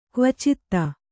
♪ kvacitta